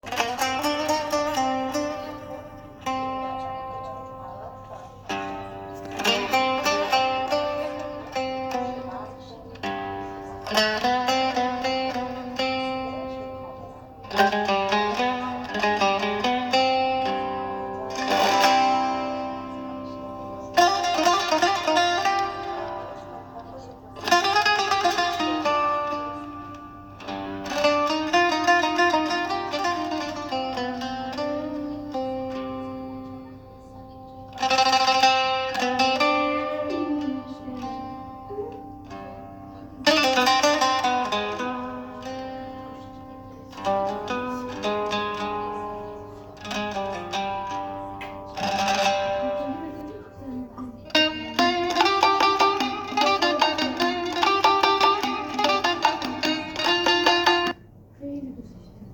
با اکانت Suno می‌تونی تو چند ثانیه یه ملودی غمگین و پراحساس برای نوحه بسازی، حتی با صدای خواننده!
تولید نوحه با هوش مصنوعی Suno